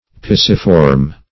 Pisiform \Pi"si*form\, n. (Anat.)